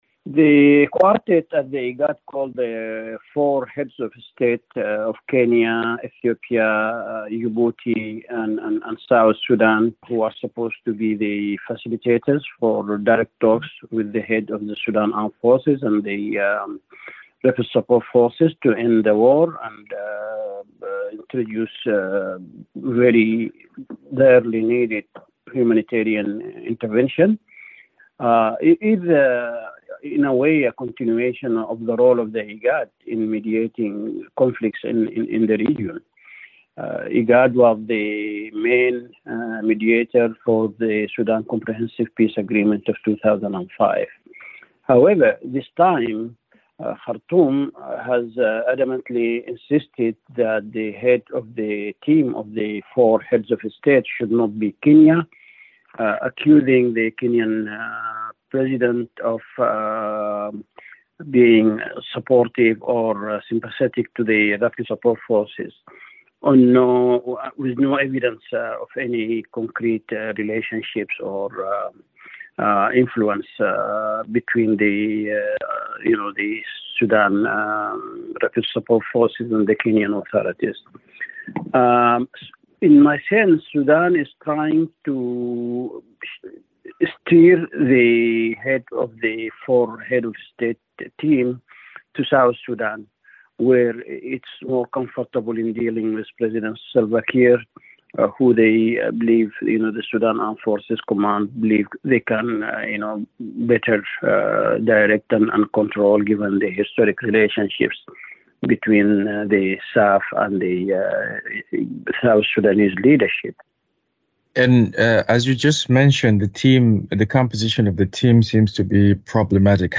Analysis: IGAD Countries Convene to Address Sudan Conflict [5:51]